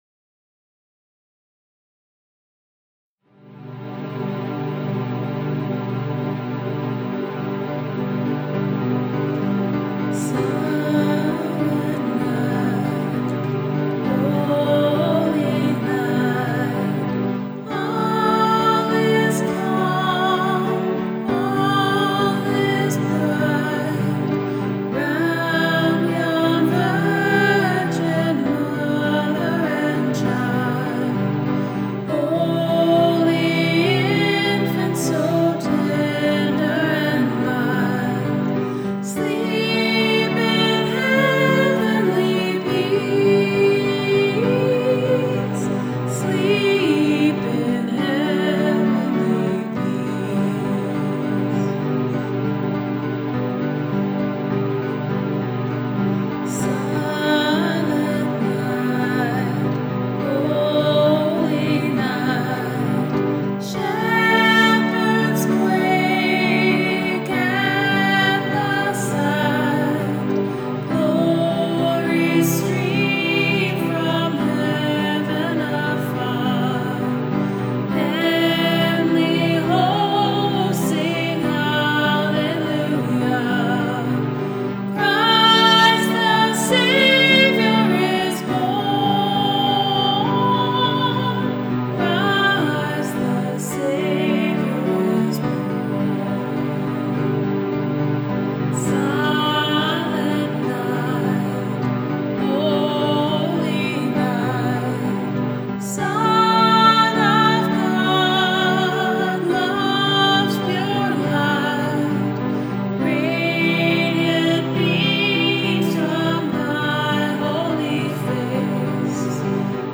LISTEN TO THE SOME CHRISTMAS FAVOURITES RECORDED BY OUR WORSHIP TEAM!